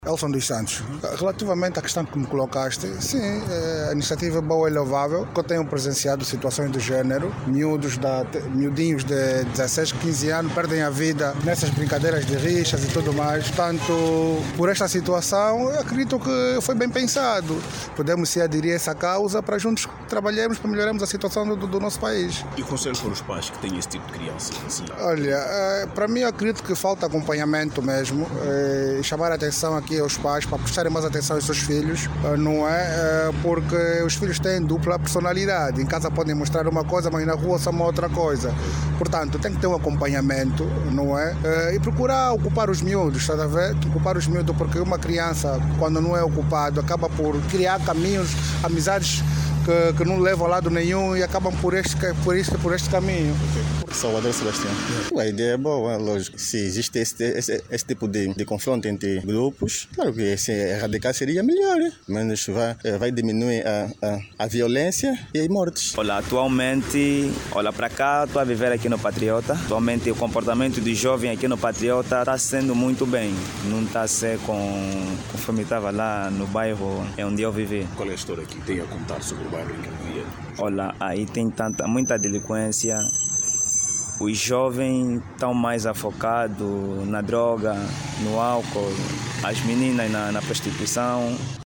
A Rádio NOVA ouviu à propósito reacções de populares.
VOX-POP-RIXA-1.mp3